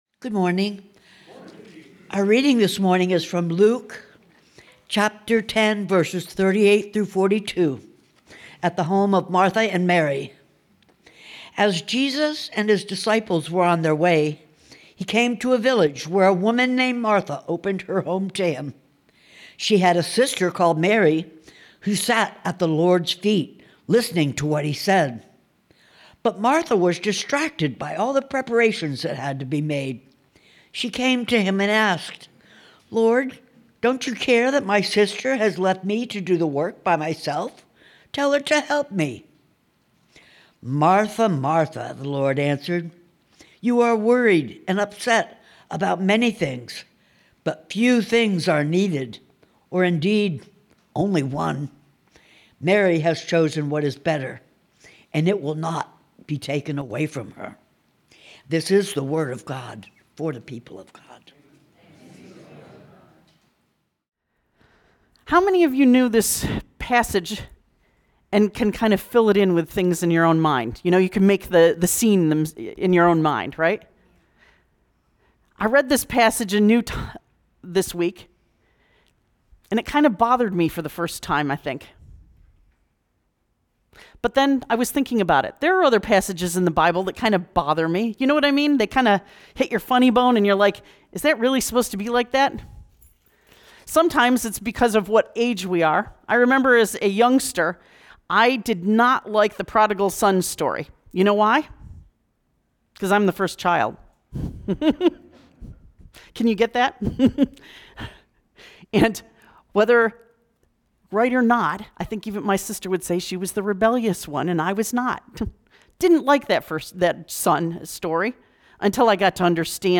March 8, 2026 Sermon Audio